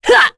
Morrah-Vox_Attack3.wav